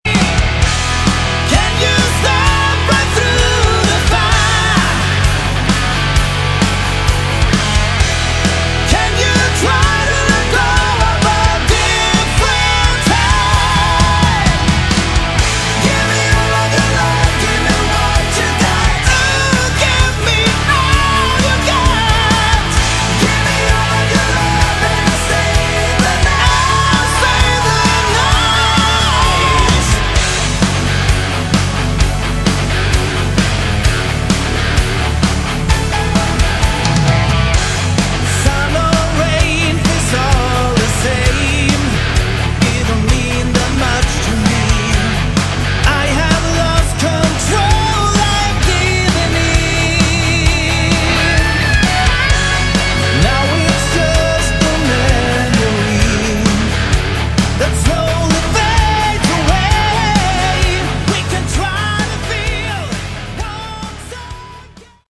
Category: Hard Rock
vocals
guitar
keyboards
bass
drums